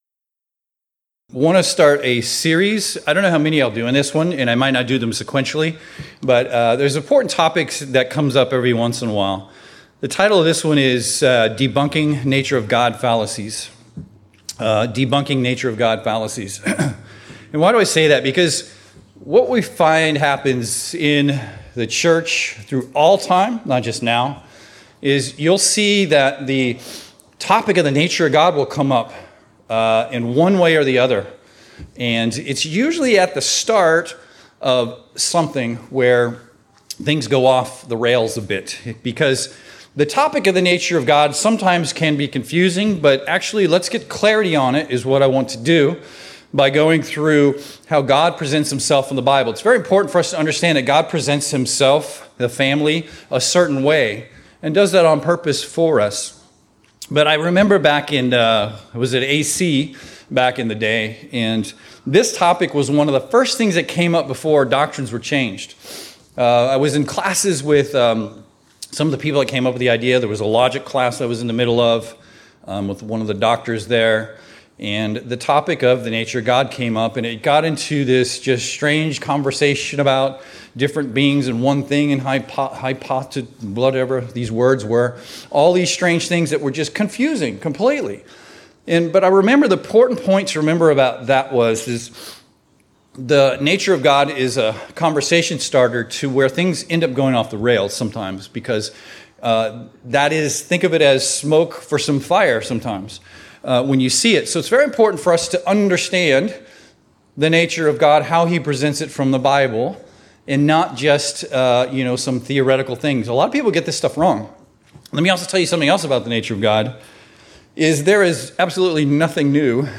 This sermon covers key points on who was the God that interacted with humans in the Old Testament, who is the Father, and how can there be two beings, yet one God.
Given in Seattle, WA